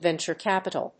アクセントvénture càpital